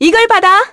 Juno-Vox_Skill1_kr.wav